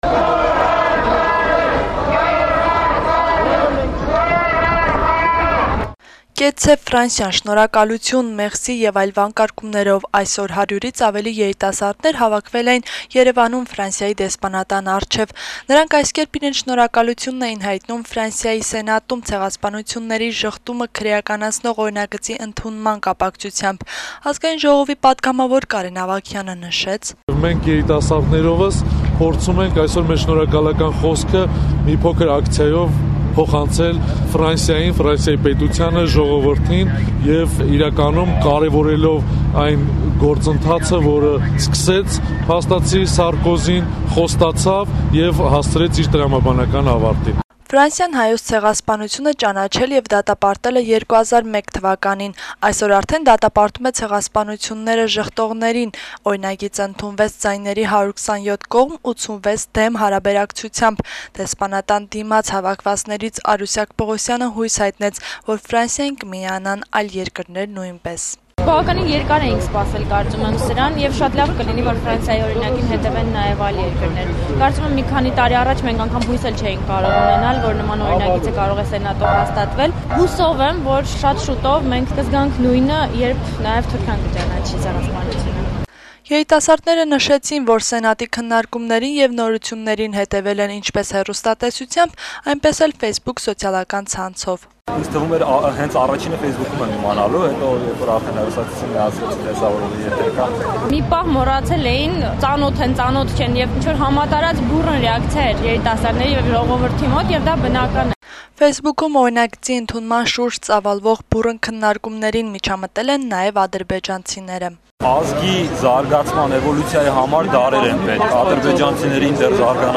«Կեցցե' Ֆրանսիան», «Շնարհակալություն», «Merci» եւ այլ վանկարկումներով այսօր հարյուրից ավելի երիտասարդներ հավաքվել էին Երեւանում Ֆրանսիայի դեսպանատան առջեւ: